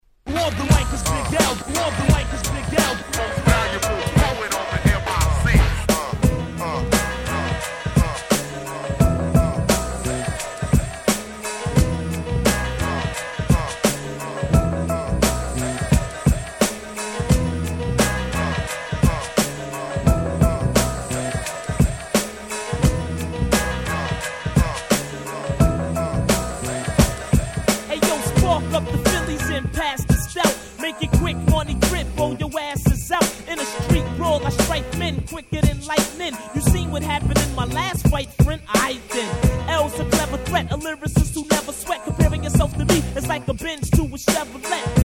(試聴ファイルは別の盤から録音してあります。)
95' Smash Hit Hip Hop !!
問答無用の90's Hip Hop Classic !!!